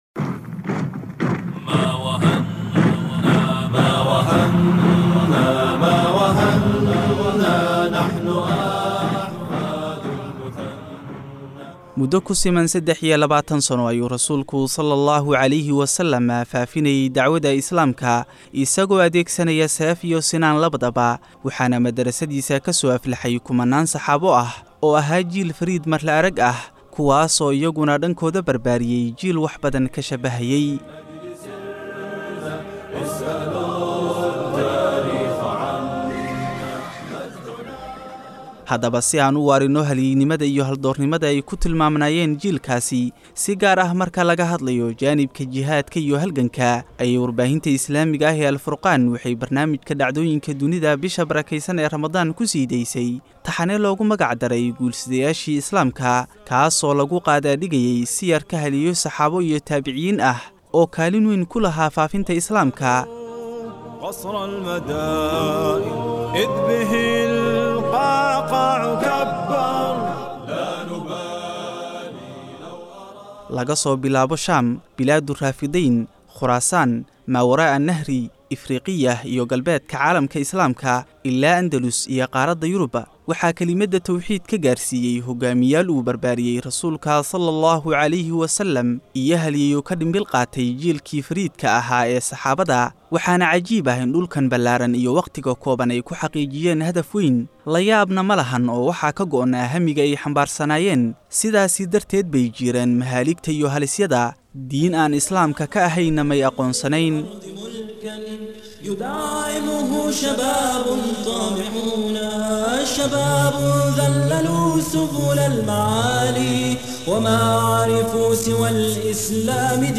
Warbaahinta Al-Furqaan oo Soo Gabagabeysay Taxanaha Guul-Sidayaashii Islaamka.[WARBIXIN]